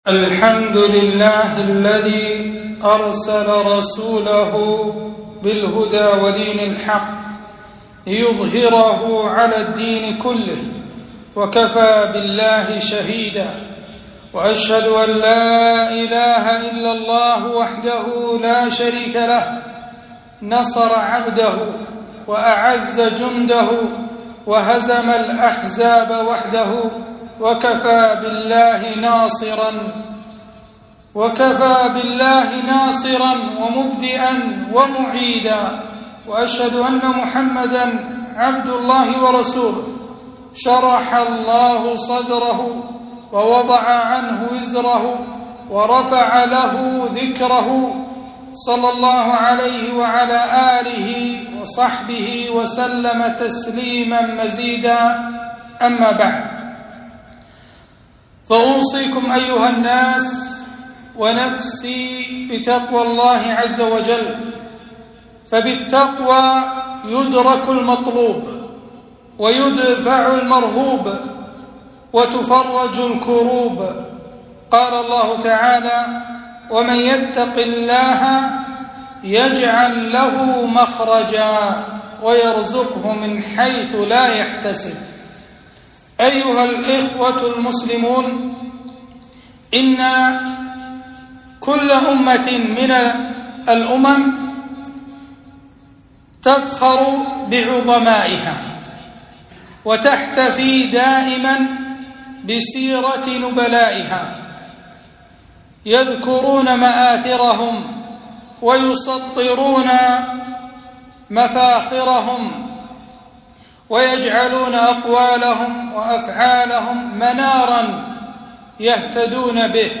خطبة - الهجرية النبوية دروس وعبر